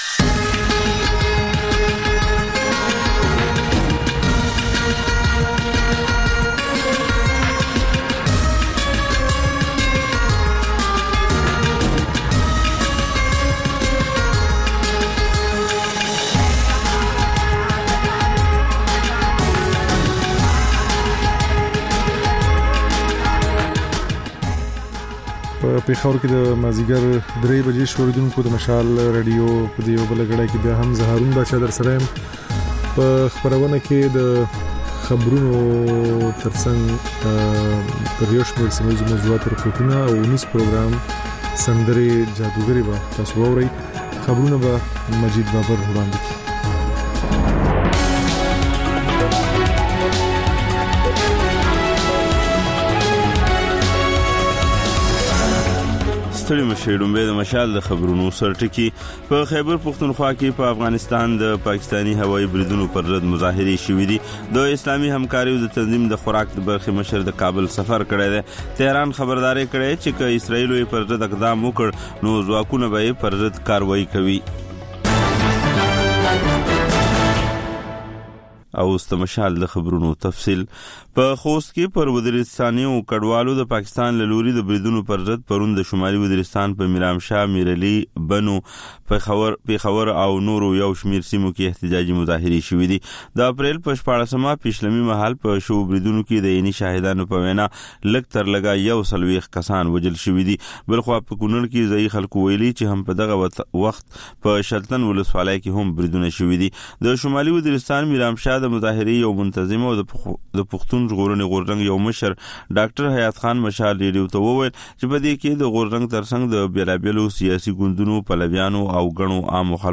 د مشال راډیو درېیمه یو ساعته ماسپښینۍ خپرونه. تر خبرونو وروسته، رپورټونه، شننې، او رسنیو ته کتنې خپرېږي.